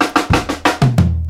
Chopped Fill 13.wav